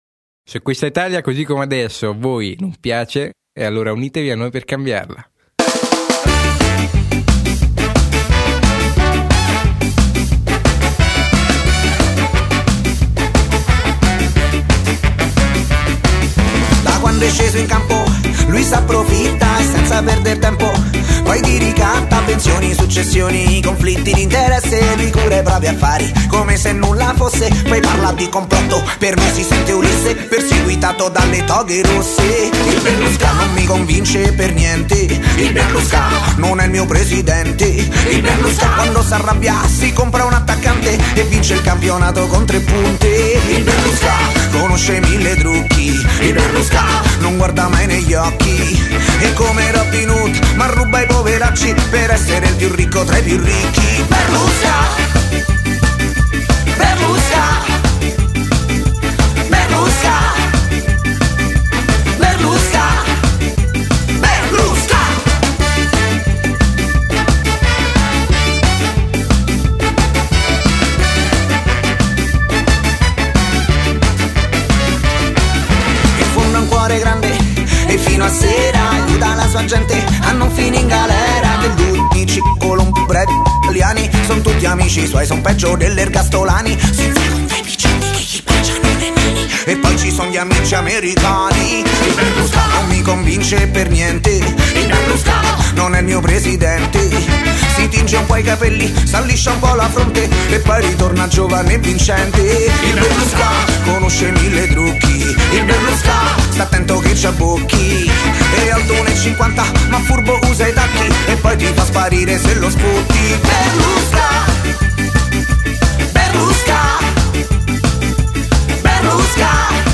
be-rlu-SKA.mp3